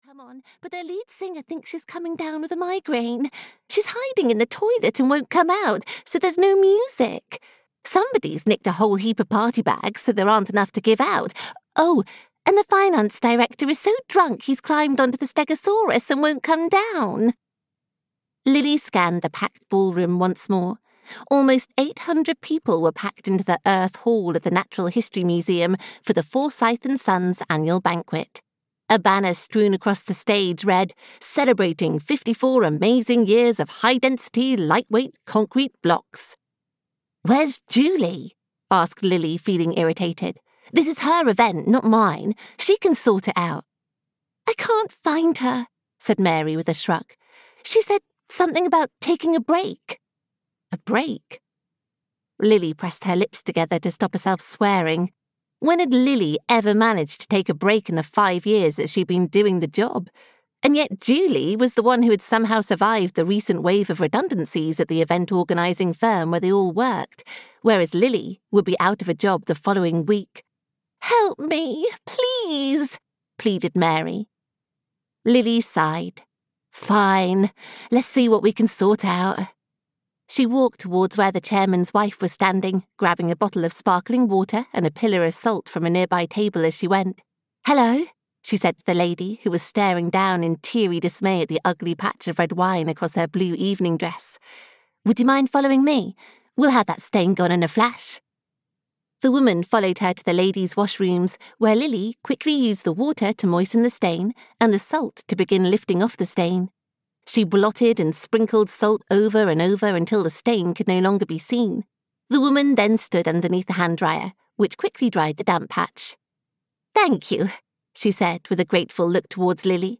Moonlight Kisses at Willow Tree Hall (EN) audiokniha
Ukázka z knihy